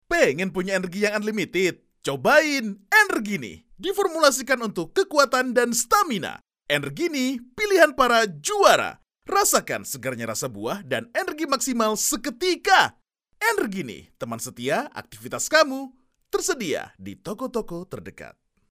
Commercieel, Stoer, Veelzijdig, Volwassen, Warm
Commercieel